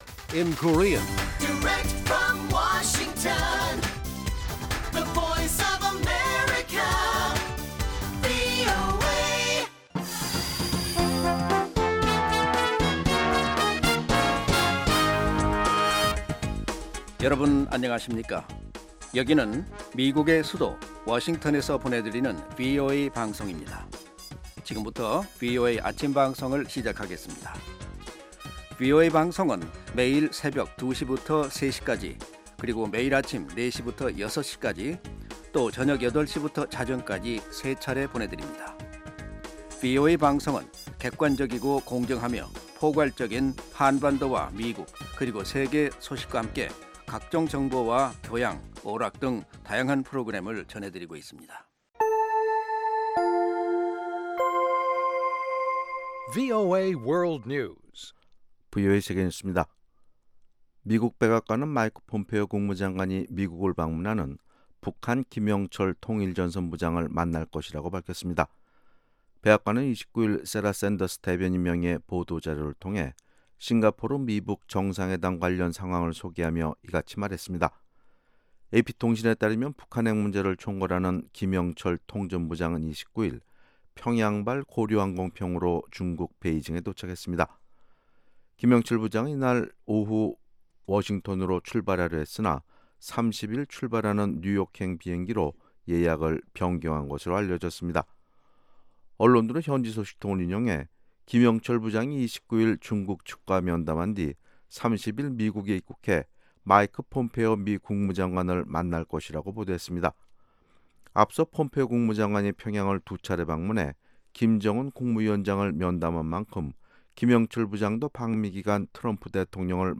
세계 뉴스와 함께 미국의 모든 것을 소개하는 '생방송 여기는 워싱턴입니다', 2018년 5월 30일 아침 방송입니다. ‘지구촌 오늘’ 에서는 미국 트럼프 정부의 중국 통신장비기업 제재 해제와 의회의 반발, ‘아메리카 나우’ 에서는 트럼프 정부의 불법 이민 가족 분리 정책에 대한 논란을 전해드립니다. ' VOA 이야기 미국사'에서는 미국의 역사를 알기 쉬운 이야기로 소개해드립니다.